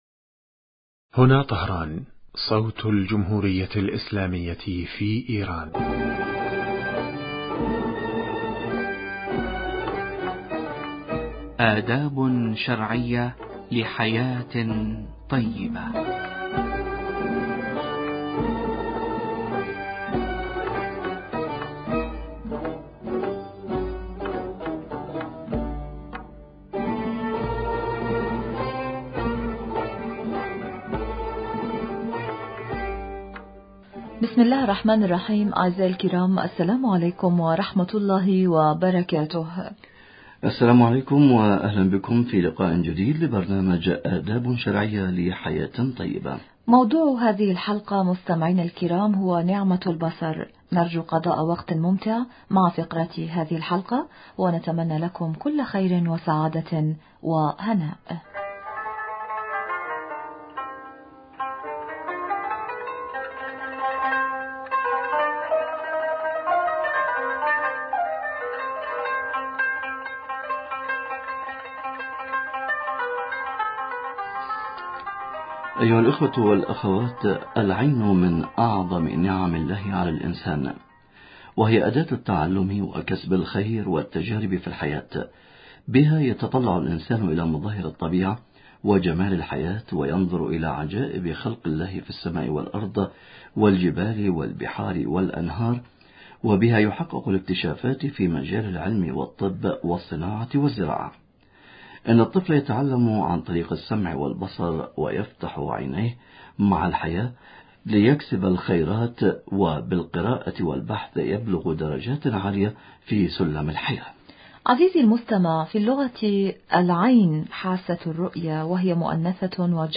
مستمعينا الكرام..